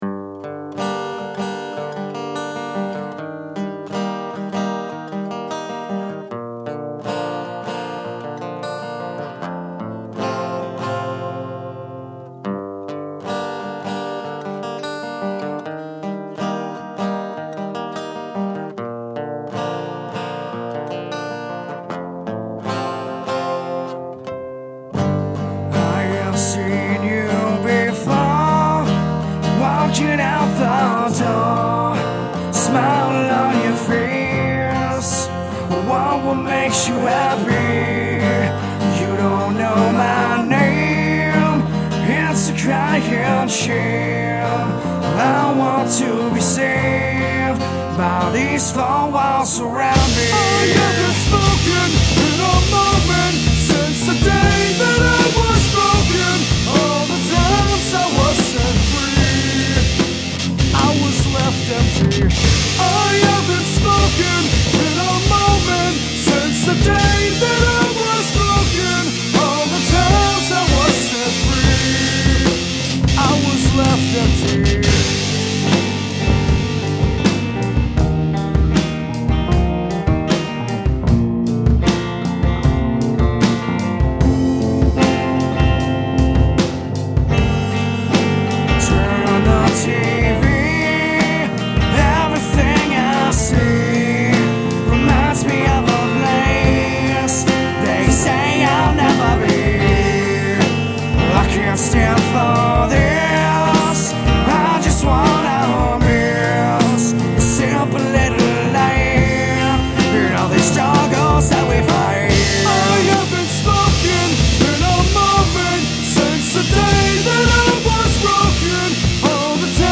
I recorded at low levels, took the time to get the mic placement right on the drums and guitar, and was generally a pain in the ass on getting everything just right.
This was only the second time I've recorded an acoustic guitar, so I don't feel like I have the feel for it yet, BUT I'm still happy with it.
So far, I've adjusted levels, used parallel compression on the drums, added a snare trigger parallel to the original track, added some EQ to guitars, vocals, (little boost on the highs, if I remember correctly) and bass (Low pass). I added light compression and a limiter on the master track, just to bring the volume up for you all to hear.